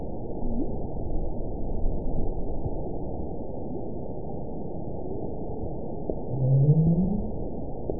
event 920863 date 04/12/24 time 00:19:18 GMT (1 year, 2 months ago) score 9.43 location TSS-AB05 detected by nrw target species NRW annotations +NRW Spectrogram: Frequency (kHz) vs. Time (s) audio not available .wav